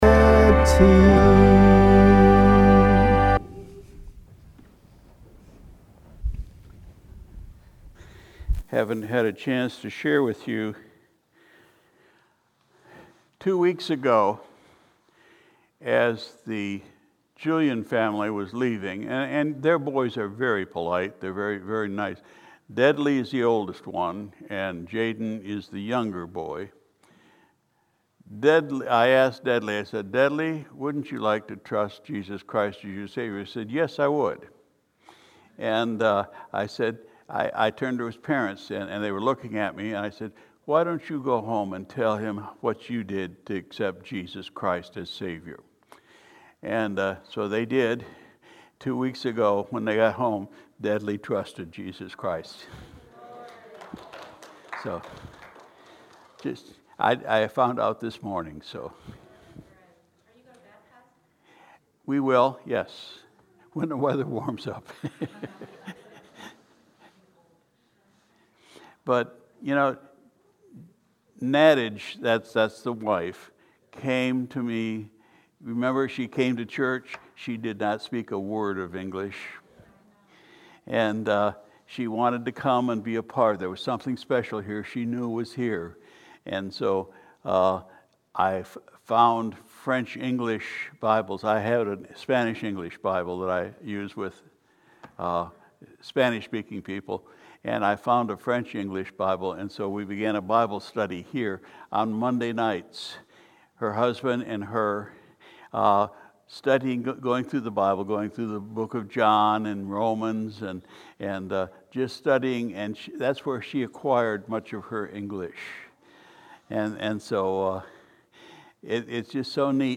January 30, 2022 Sunday Evening Service We continued our study in the Book of Revelation